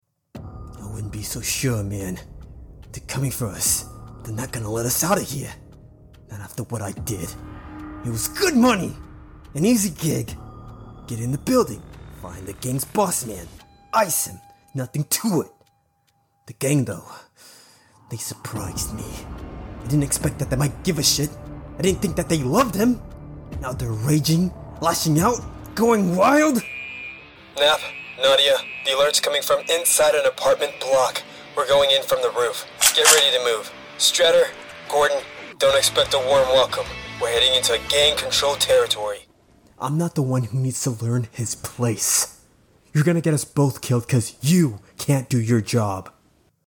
Action demo
English (United Kingdom)
Young Adult
Action demo reel.MP3